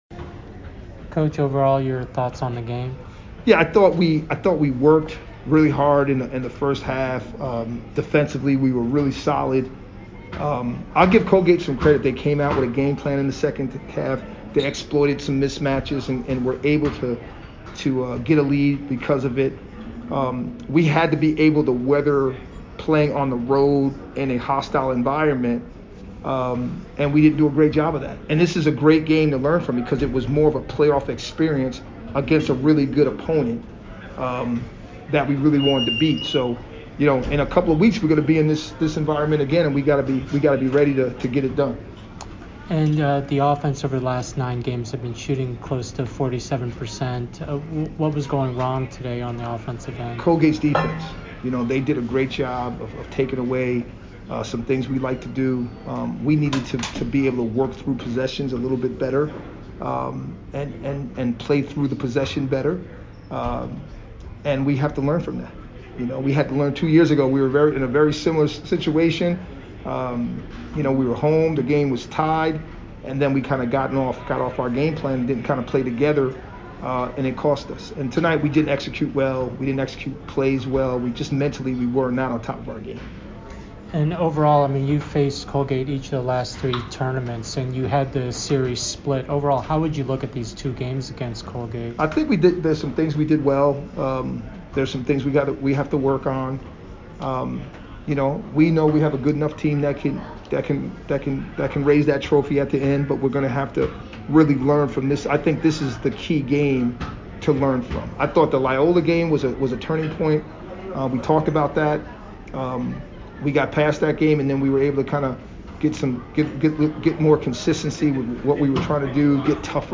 Colgate MBB Postgame Interview